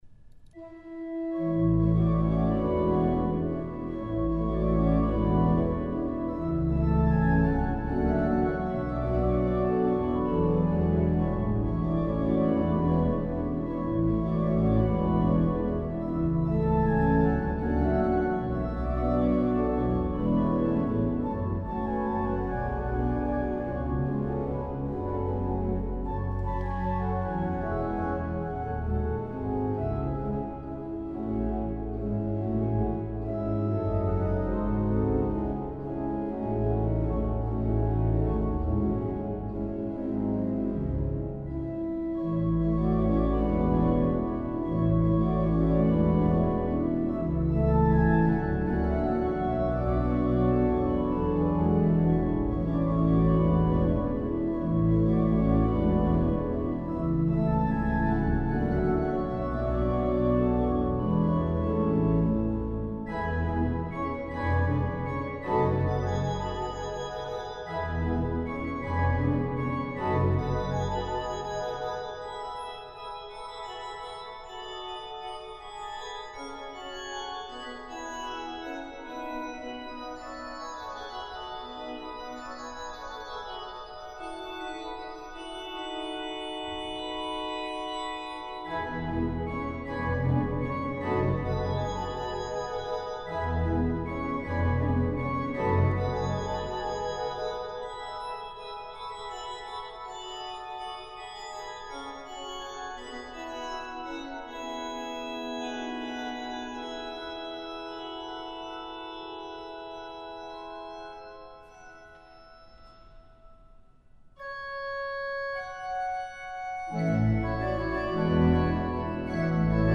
orgel
orgel i Hedvig Eleonora kyrka Fr�n v�ran f�rsta inspelning den 17 oktober 2006 �ter min hemsida